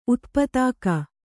♪ utpatāka